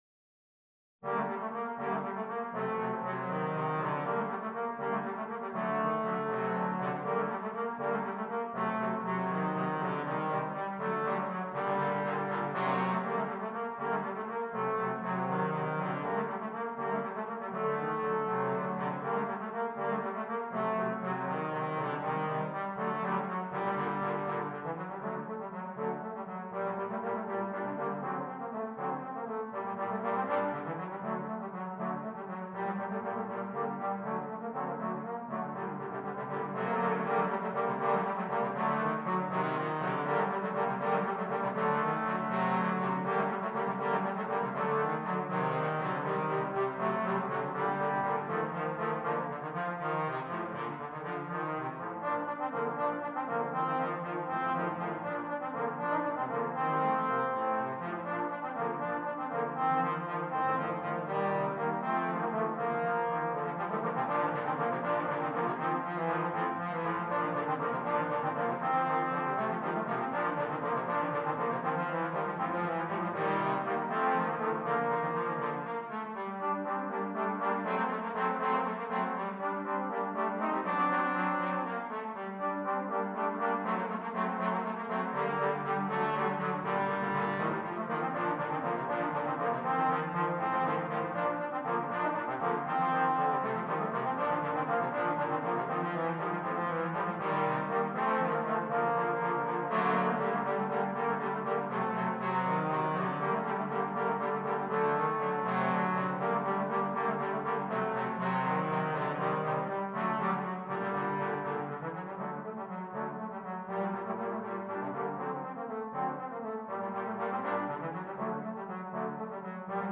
3 Trombones